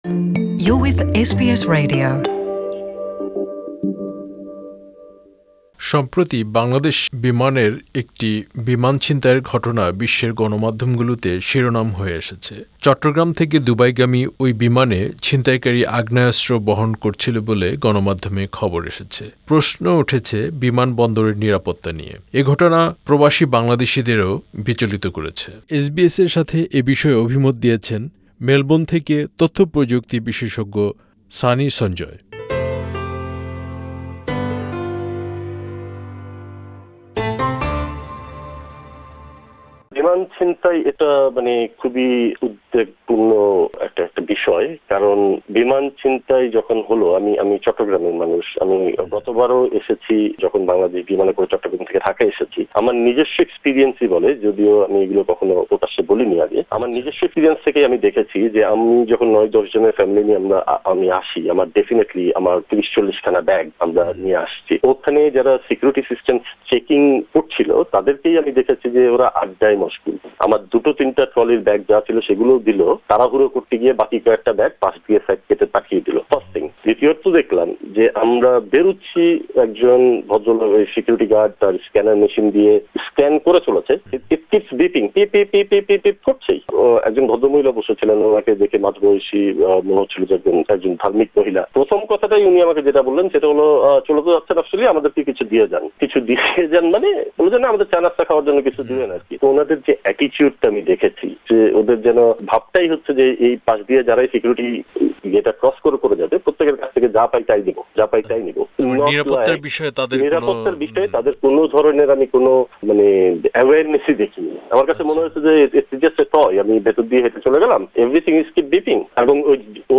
বাংলাদেশে বিমানবন্দরগুলোতে নিরাপত্তা ব্যবস্থা ও যাত্রীসেবার মান নিয়ে প্রশ্ন উঠেছে। এসবিএস বাংলার সঙ্গে এ বিষয়ে কথা বলেছেন অস্ট্রেলিয়া প্রবাসী কয়েকজন বাংলাদেশী।